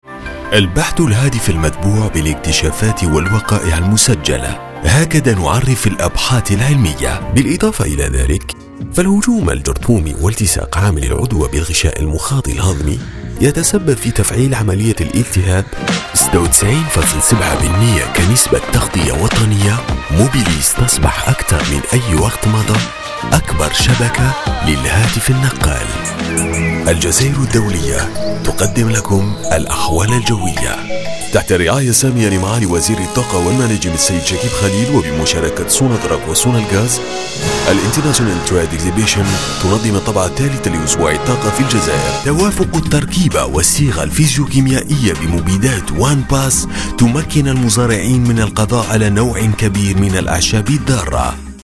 Comédien voix off en langue arabe, réalisateur radio, chanteur, imitateur depuis plus de 20 ans, je met chaque jour ma voix et mon expérience à votre service pour tout enregistrement de voix : pub radio ou TV, commentaire de film institutionnel, film d\'entreprise, habillage d\'antenne, audiotel, billboard, composition, cartoon, comédie radio, bande annonce, signature, attente téléphonique, jingle, voice over....
Sprechprobe: Werbung (Muttersprache):